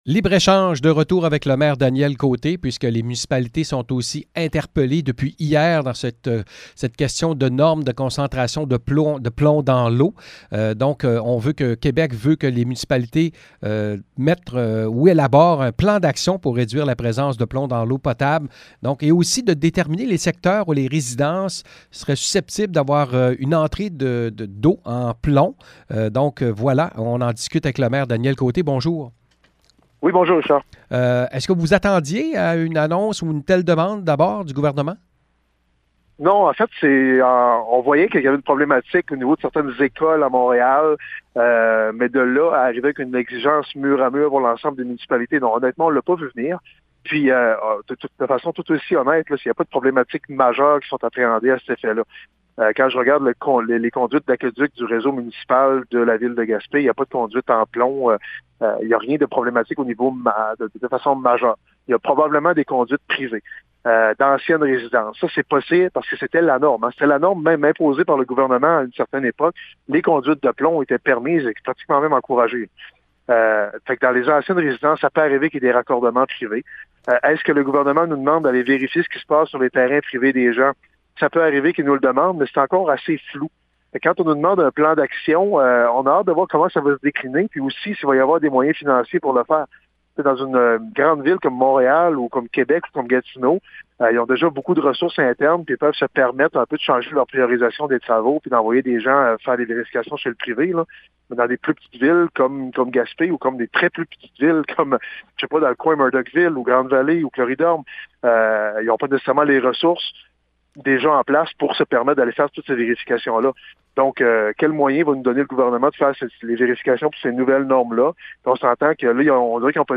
Entrevue avec le maire de Gaspé, Danieel Côté: